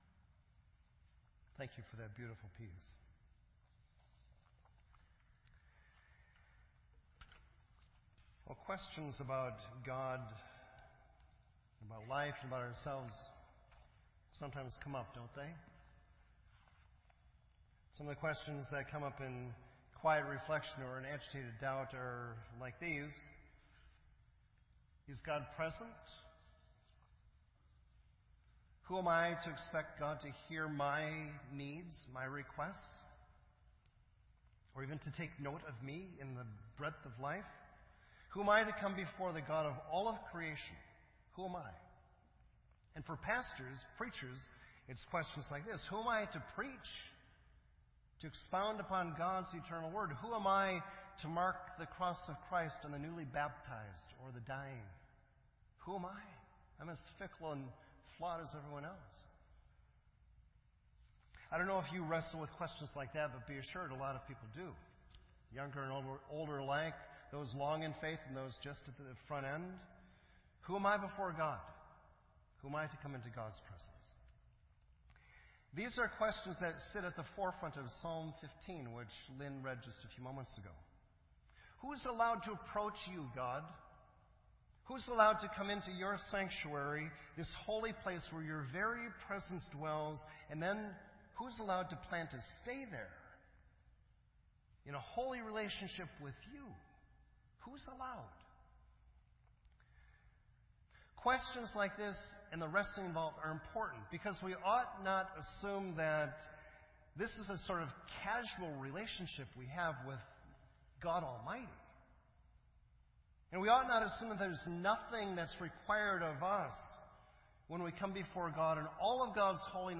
This entry was posted in Sermon Audio on July 2